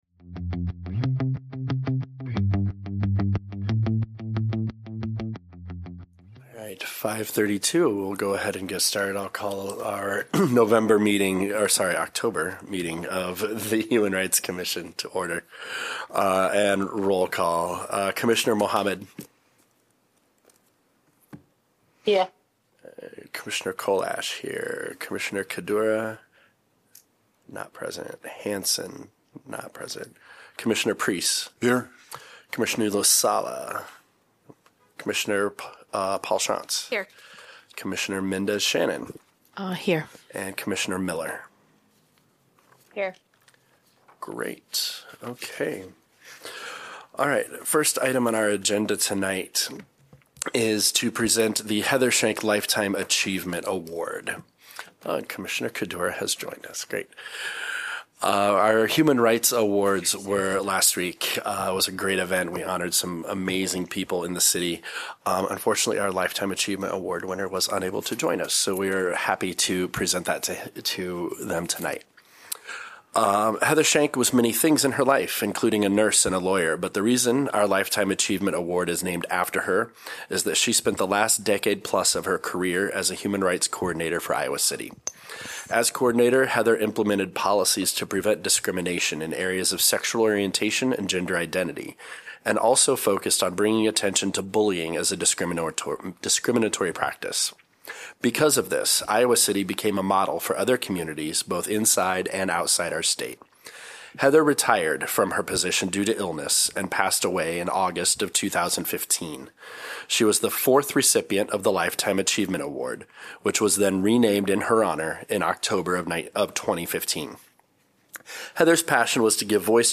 Regular monthly meeting of the Human Rights Commission.